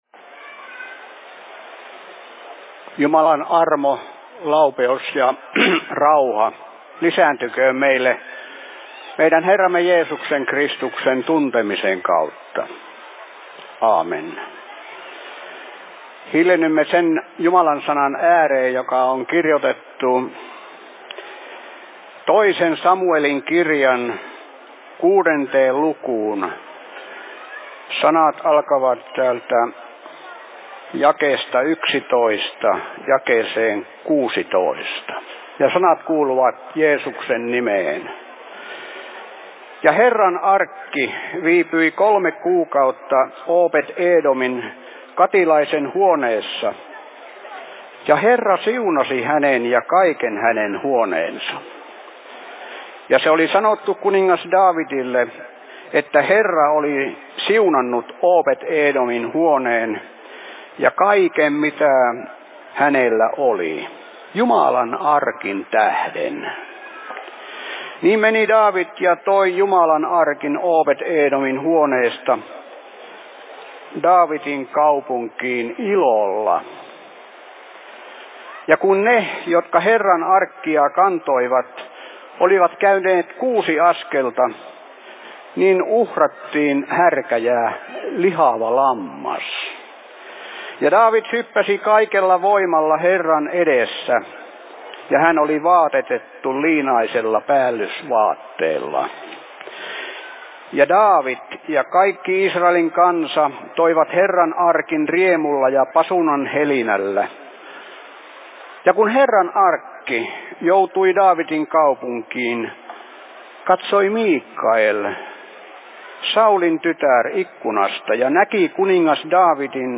Suviseurat Kauhavalla/Seurapuhe 03.07.2023 11.00
Paikka: 2023 Suviseurat Kauhavalla